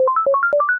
upward_beep_chromatic_fifths.ogg